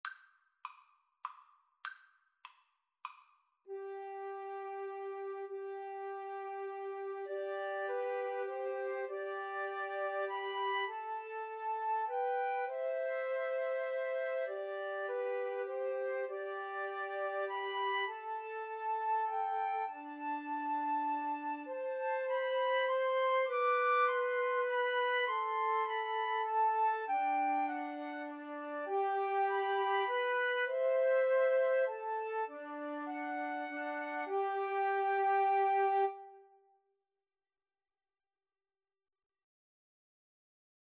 17th-century English folk song.
G major (Sounding Pitch) (View more G major Music for Flute Trio )
3/4 (View more 3/4 Music)
Moderato